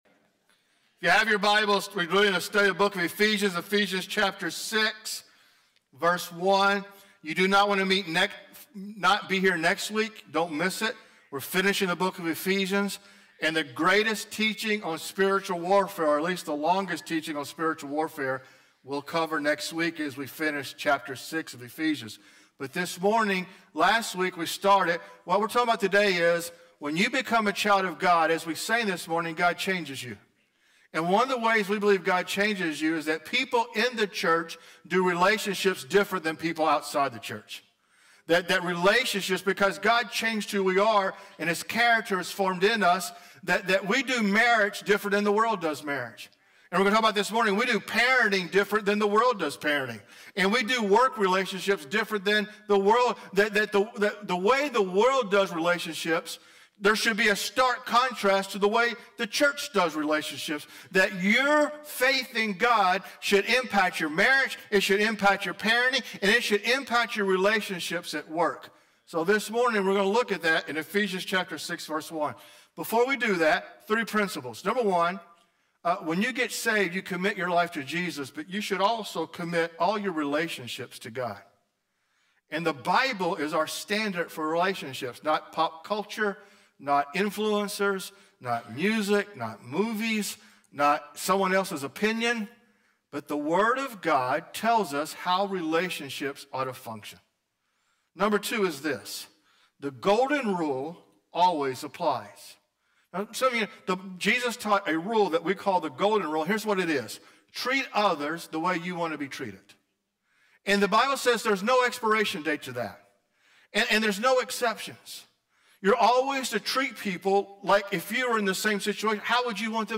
Sermons | First Assembly of God